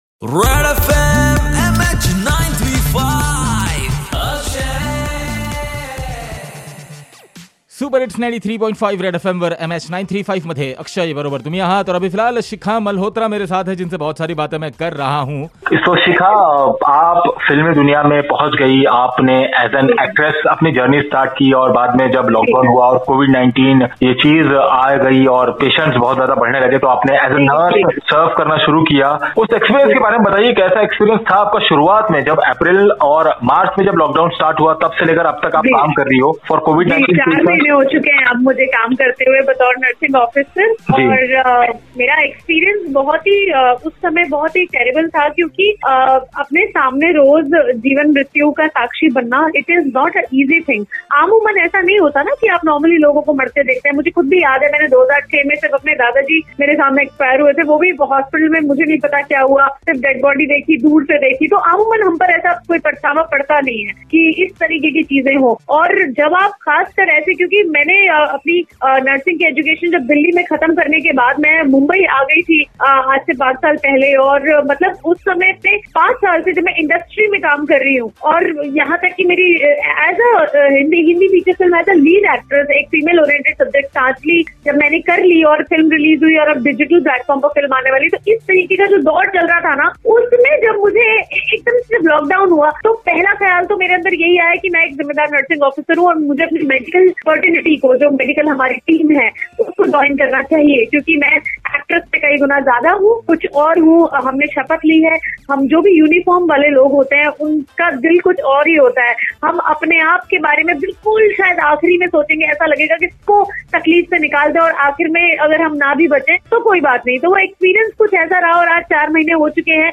RAKSHABANDHAN SPECIAL INTERVIE ) THANK YOU SISTERS FOR TAKING CARE OF COVID PATIENTS